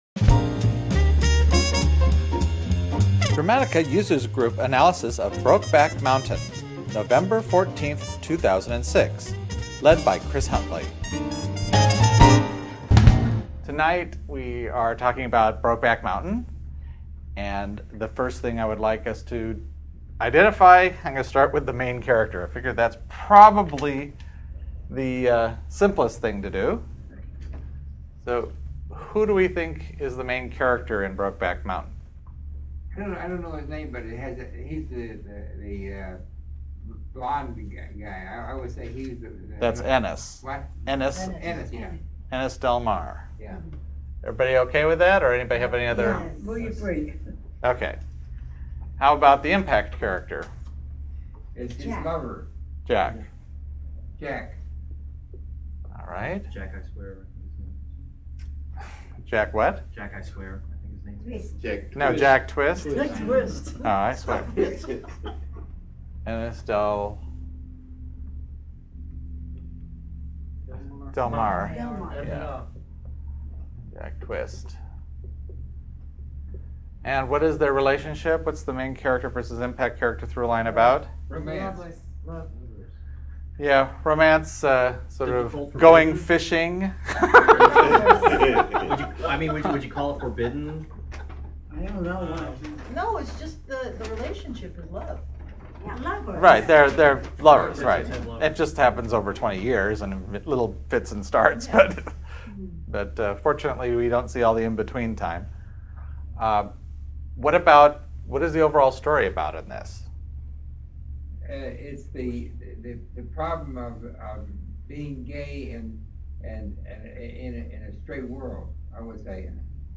Delightful recordings of Dramatica Users attempting to better understand what makes great stories so great. Each podcast focuses on a popular or critically-acclaimed film. By breaking down story into fine detail, the group gains a better appreciation of the theory and how better to apply the concepts into their own work.